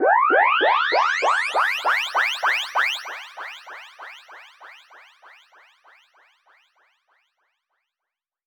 SouthSide Trap Transition (15).wav